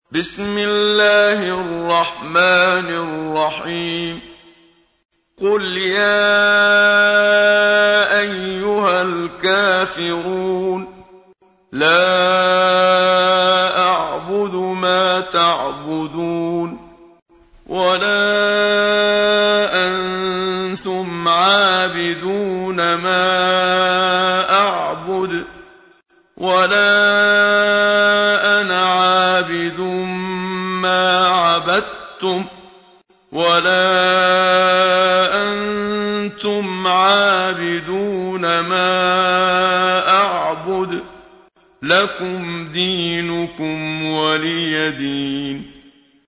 تحميل : 109. سورة الكافرون / القارئ محمد صديق المنشاوي / القرآن الكريم / موقع يا حسين